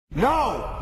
The No Button soundboard meme — iconic and funny “No” button audio clip, perfect for memes and reaction moments.